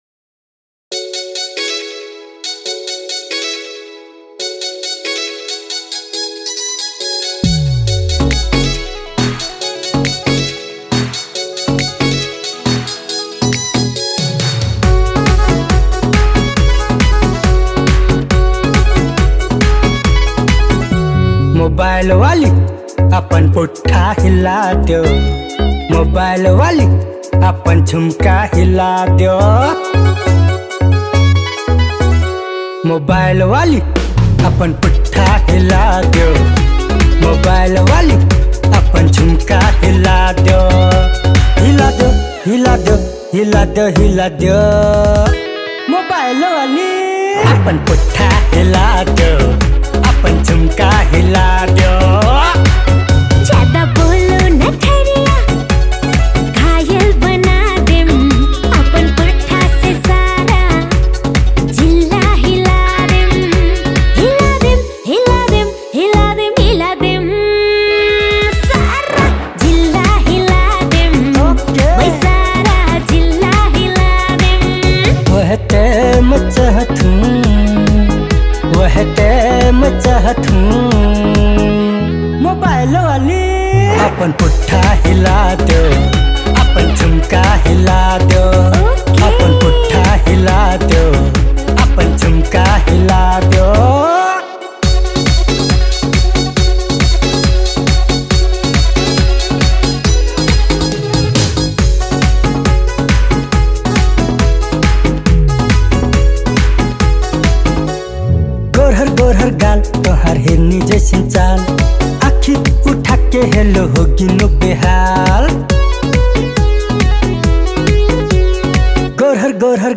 Tharu Item Dancing Song